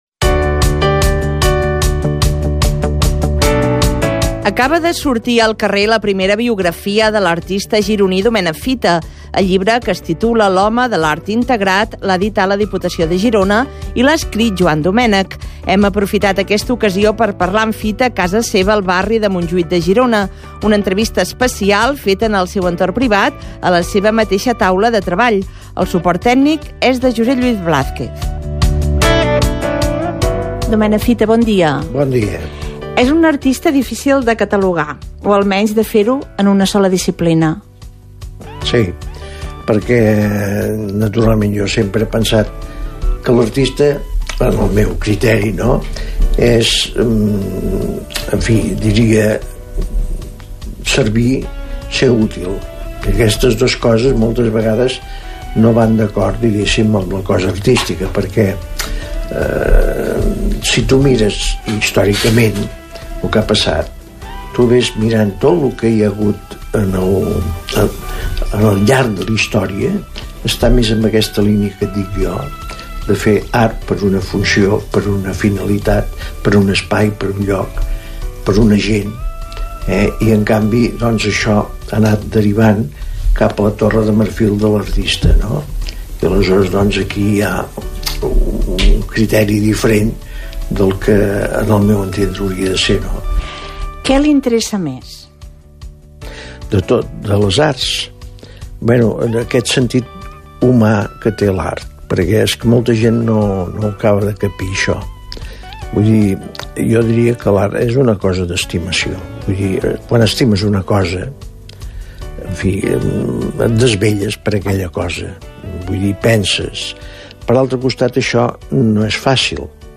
Entrevista Domènec Fita- Catalunya Ràdio, 2014
Àudios: arxiu sonor i podcast de Catalunya Ràdio